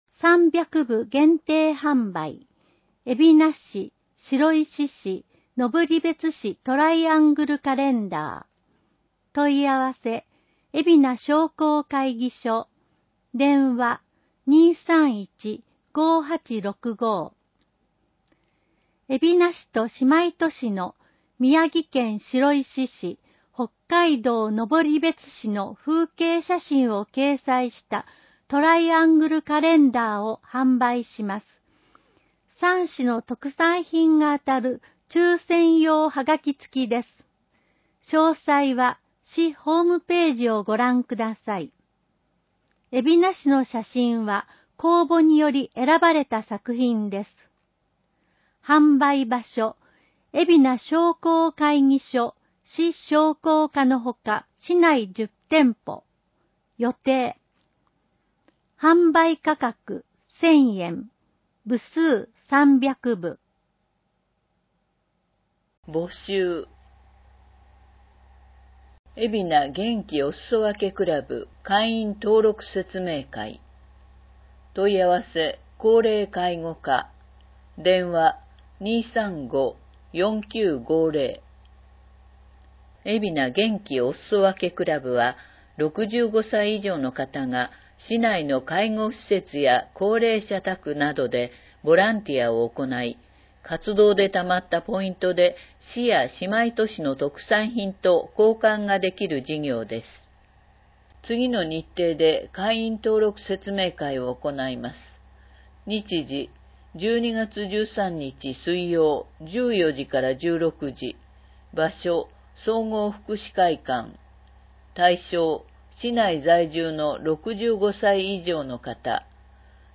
広報えびな 平成29年12月1日号（電子ブック） （外部リンク） PDF・音声版 ※音声版は、音声訳ボランティア「矢ぐるまの会」の協力により、同会が視覚障がい者の方のために作成したものを登載しています。